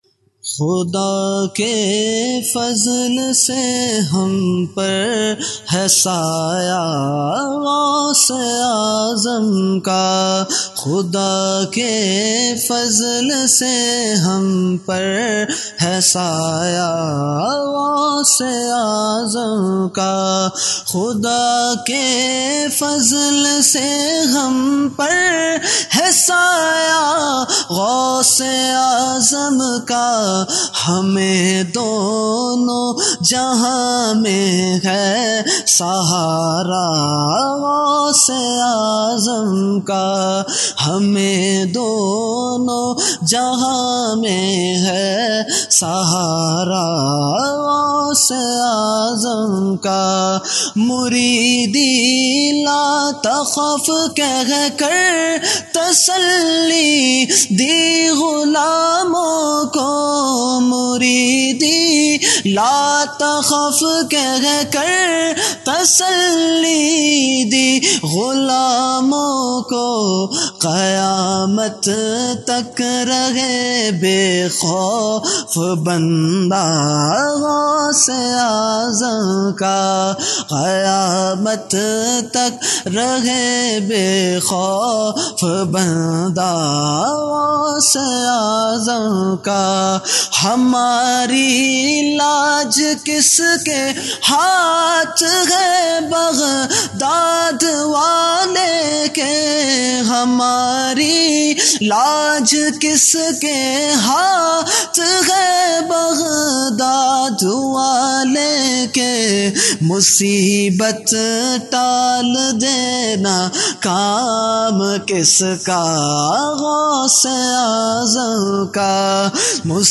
Category : Manqabat | Language : UrduEvent : Shab e Baraat 2020